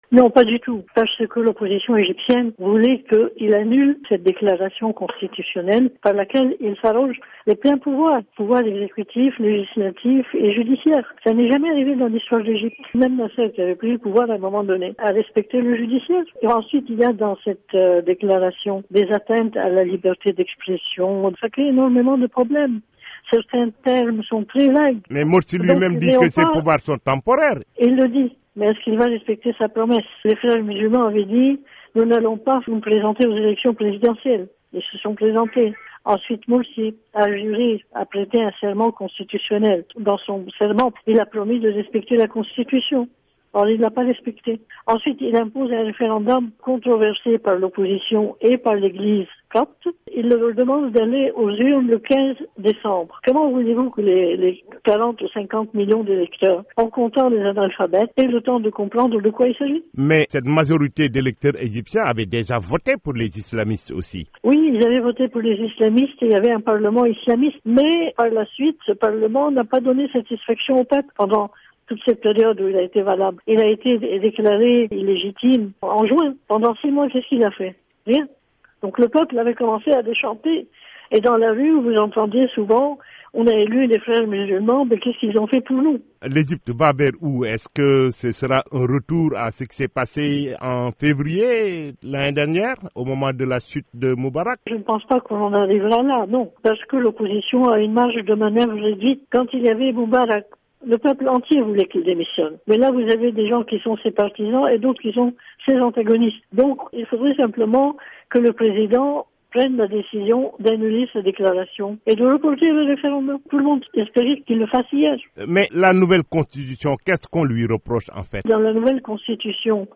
journaliste au Caire